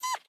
sounds / mob / fox / idle2.ogg